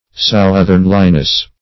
Southernliness \South"ern*li*ness\, n.